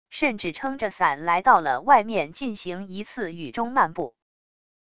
甚至撑着伞来到了外面进行一次雨中漫步wav音频生成系统WAV Audio Player